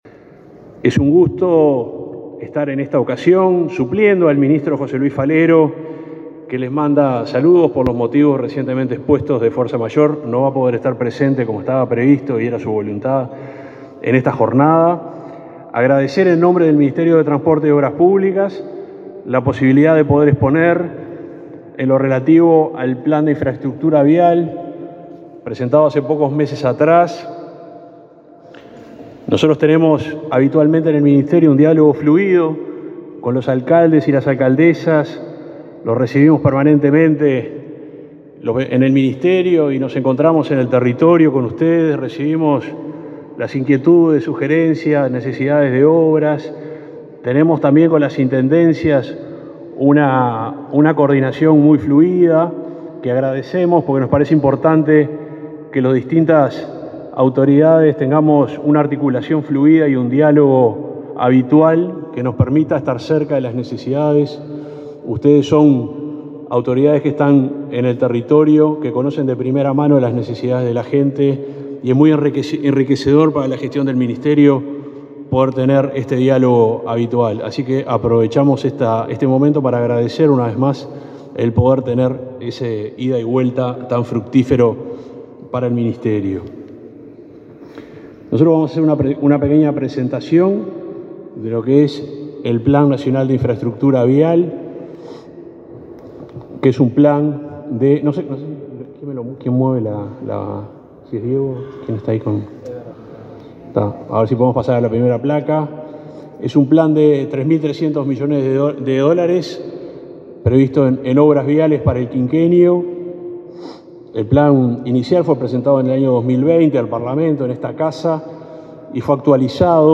Disertación del subsecretario de Transporte y Obras Públicas, Juan José Olaizola
Disertación del subsecretario de Transporte y Obras Públicas, Juan José Olaizola 31/10/2022 Compartir Facebook X Copiar enlace WhatsApp LinkedIn El subsecretario de Transporte, Juan José Olaizola, participó de la XI sesión del Plenario de Municipios de Uruguay, este lunes 31 en el Palacio Legislativo, donde presentó el Plan Nacional de Infraestructura Vial a cargo de esa cartera.